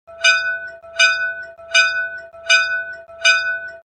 TrainBell.wav